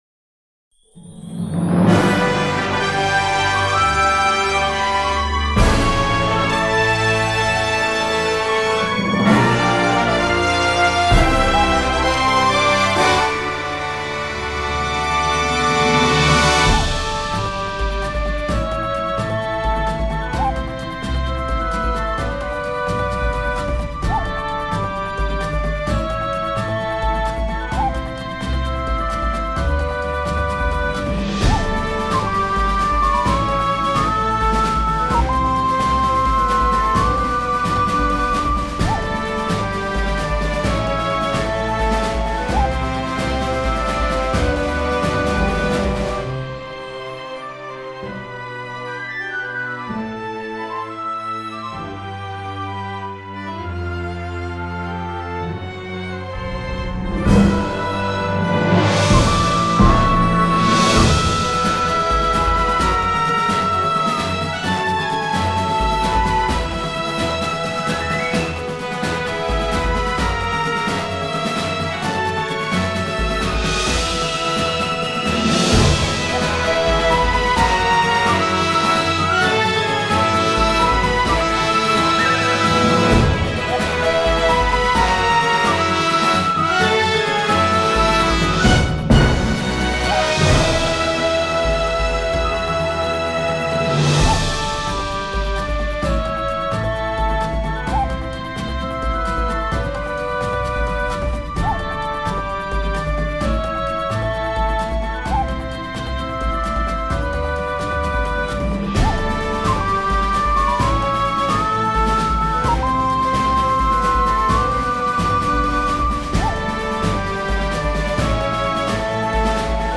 RPGにおける場面の演出、メニュー画面、選択画面などに使えそうな壮大なBGMです。